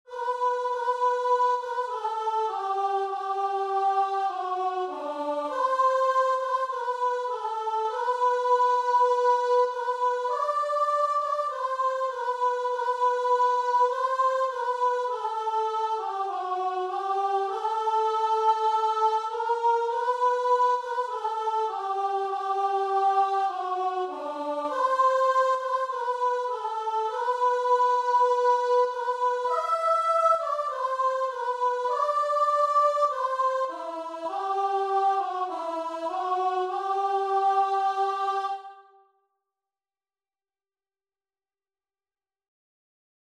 Christian
4/4 (View more 4/4 Music)
Guitar and Vocal  (View more Easy Guitar and Vocal Music)
Classical (View more Classical Guitar and Vocal Music)